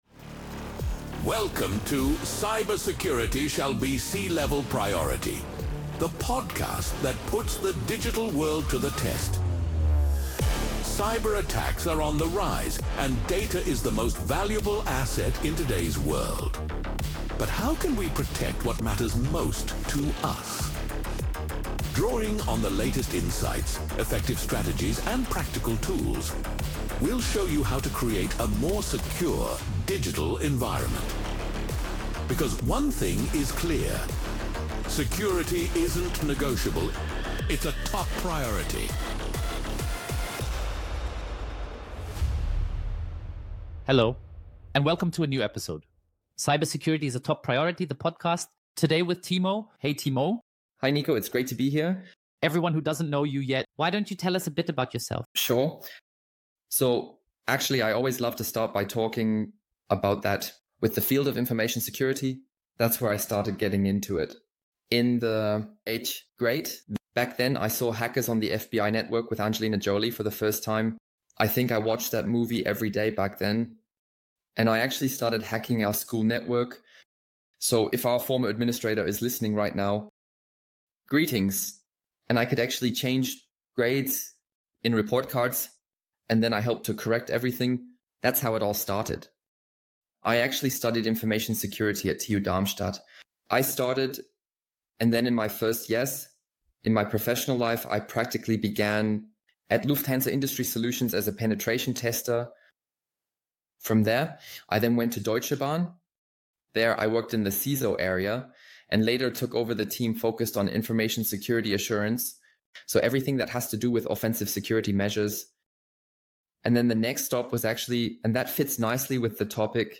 LinkedIn-Profile ____________________________________________ 🚨 This Podcast is translated from the original content Cybersecurity ist Chefsache using AI technology to make them accessible to a broader audience.